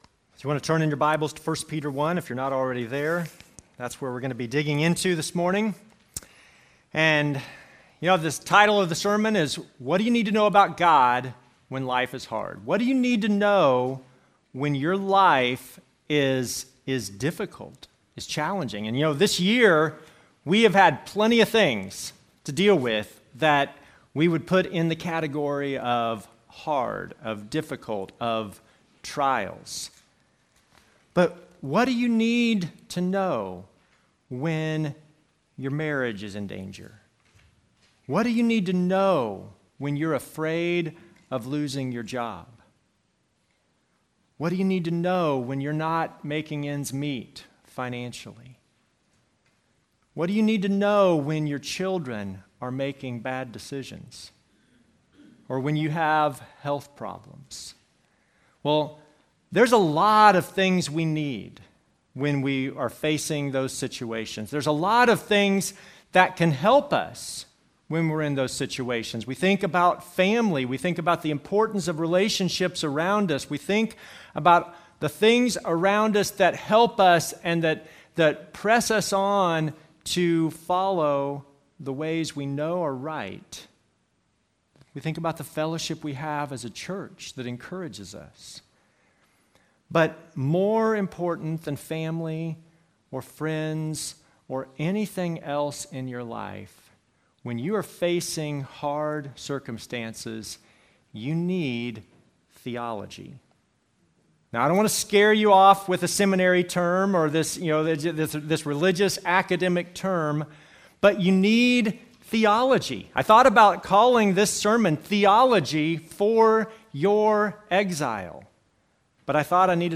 Passage: 1 Peter 1:3-12 Service Type: Normal service